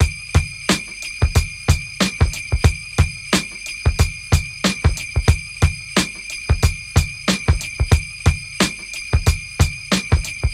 • 91 Bpm Drum Beat D Key.wav
Free breakbeat - kick tuned to the D note.
91-bpm-drum-beat-d-key-w0w.wav